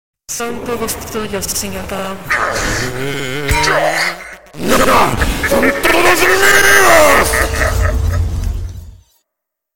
FANDUB ESPAÑOL LATINO✨